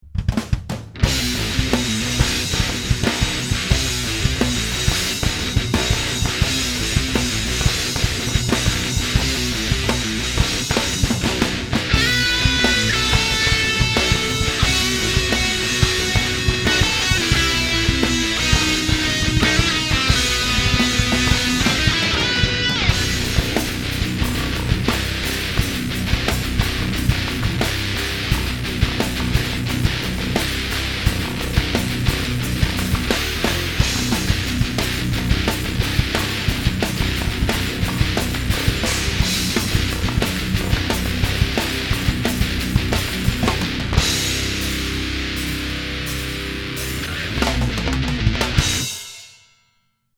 Here’s the closing theme, also with no voiceover.